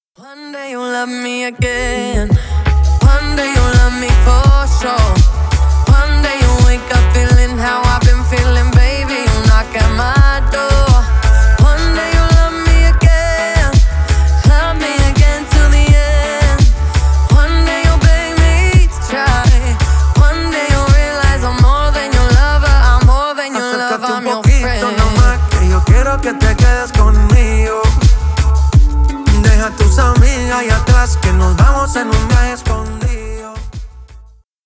Поп Музыка
латинские